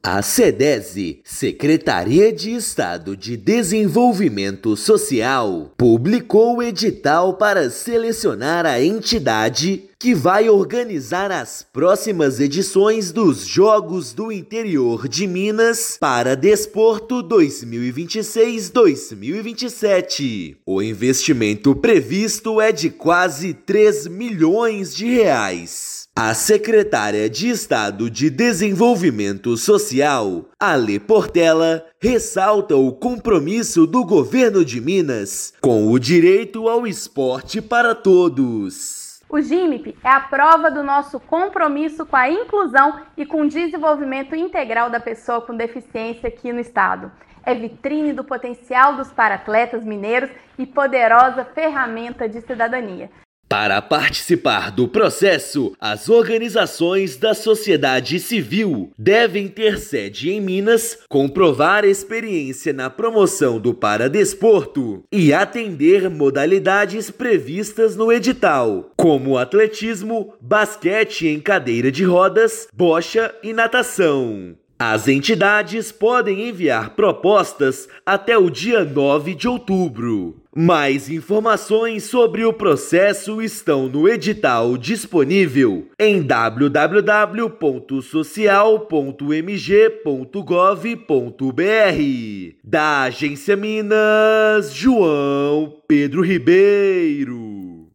Já referência na inclusão de pessoas com deficiência no esporte, programa da Sedese segue crescendo. Ouça matéria de rádio.